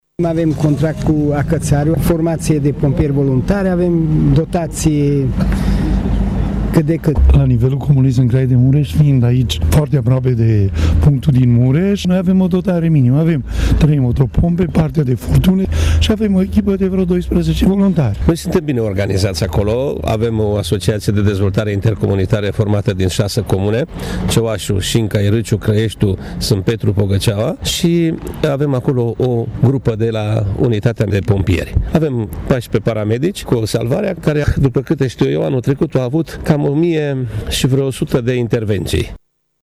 Primarii intervievați de Radio Tîrgu-Mureș s-au declarat mulțumiți atât de colaborarea cu ISU Mureș, cât și de dotările punctelor de lucru.